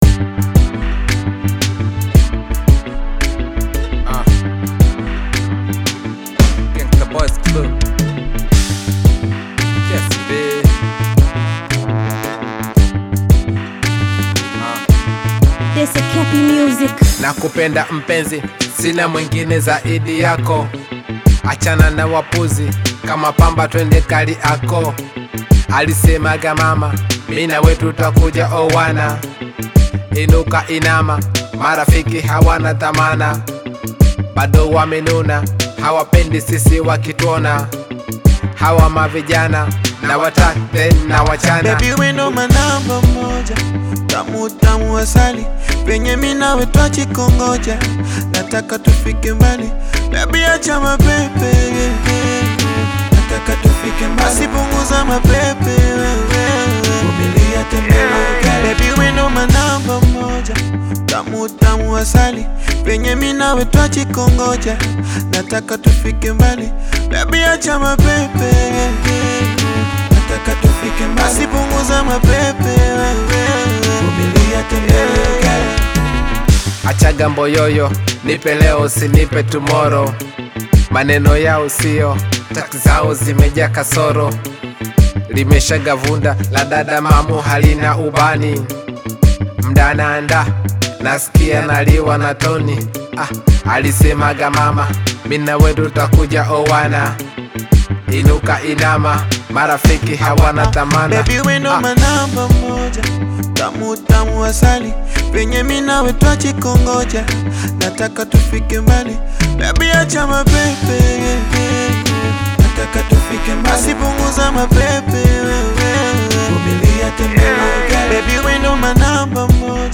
Tanzanian Afro-Beat/Afropop single
delivering catchy rhythms and energetic vibes.